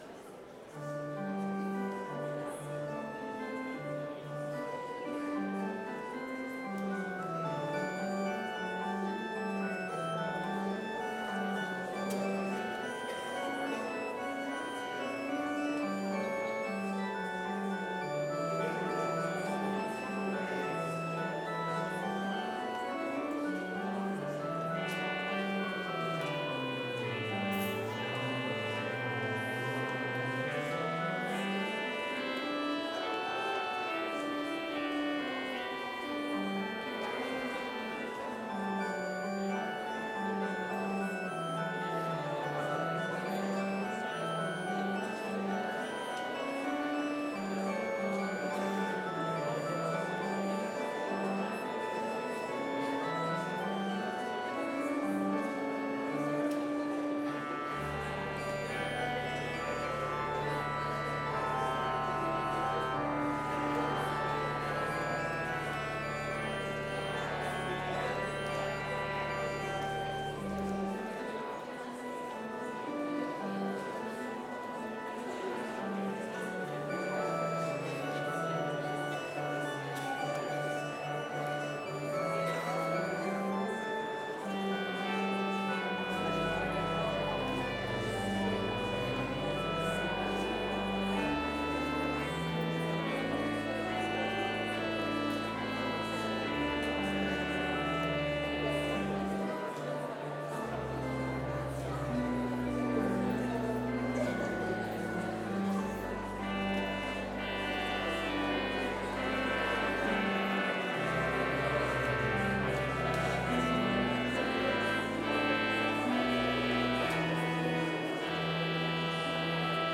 Chapel service in Bethany Lutheran College's chapel
Complete service audio for Chapel - Tuesday, November 26, 2024